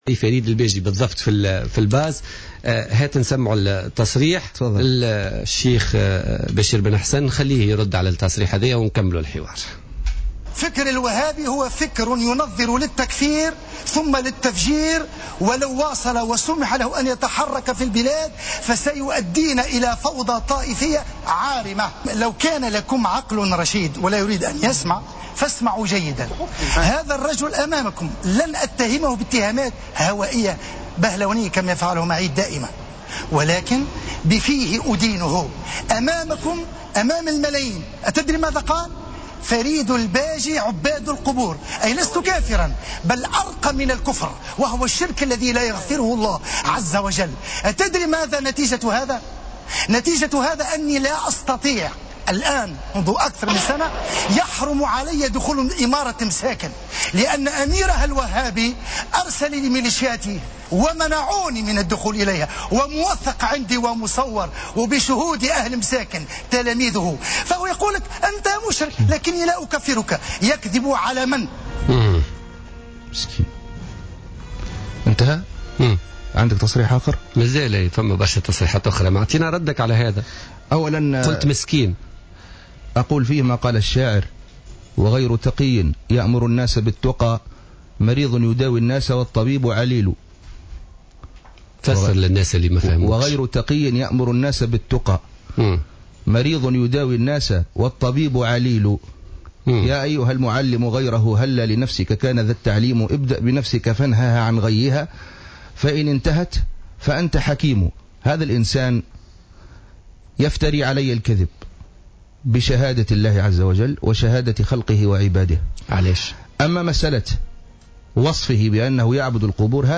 ضيف حصة بوليتيكا ليوم الخميس 02 أفريل